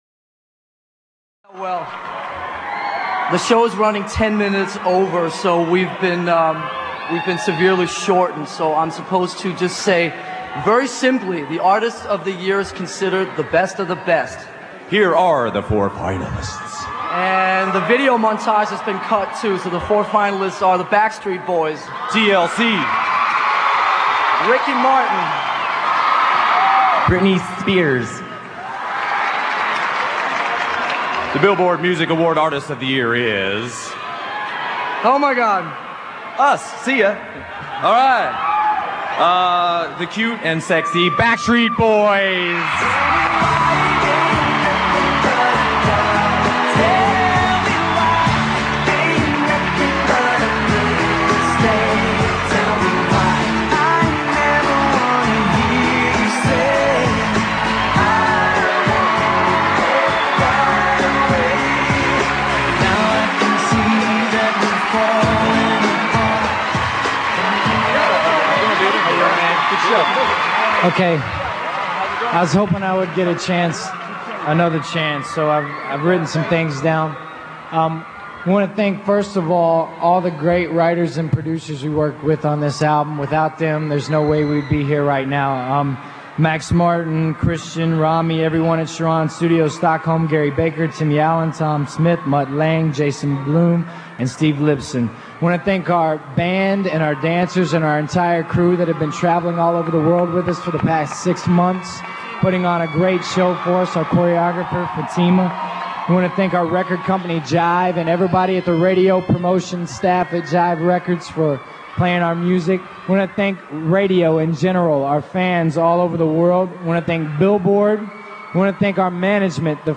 Billboard Awards 2 2:45 | 426 KB Winning the '99 Artist Of The Year award.